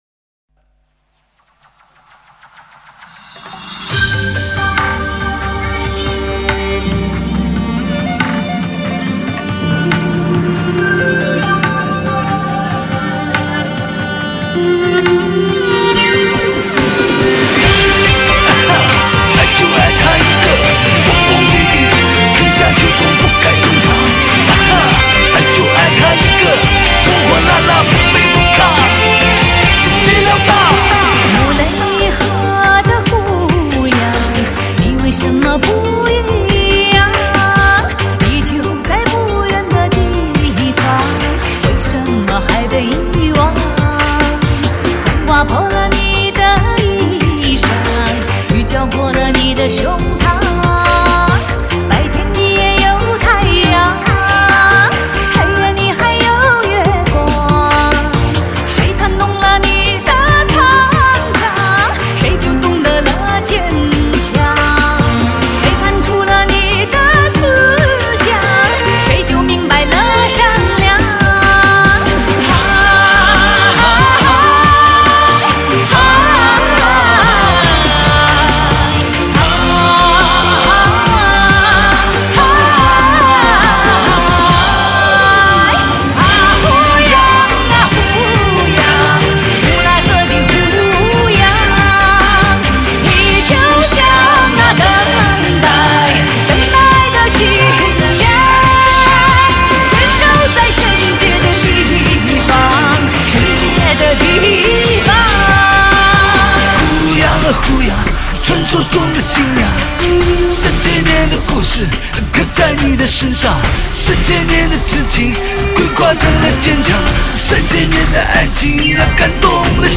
由两位歌手组成